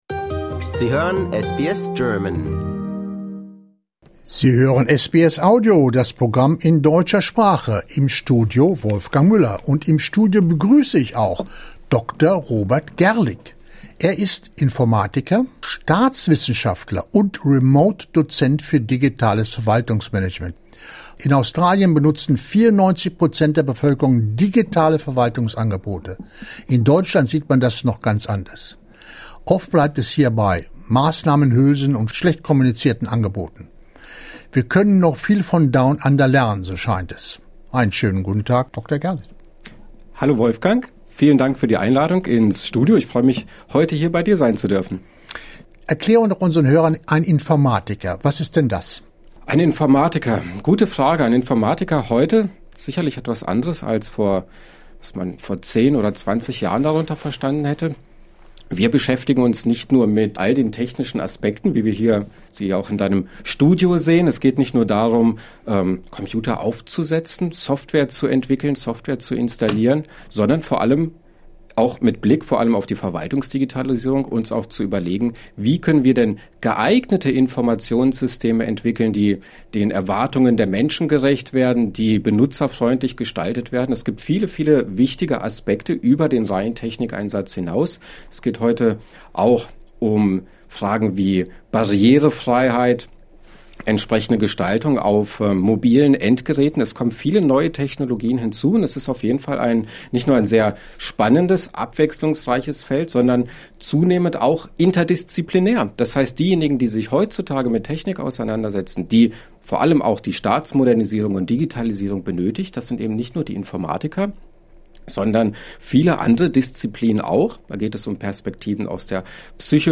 im Studio von SBS Audio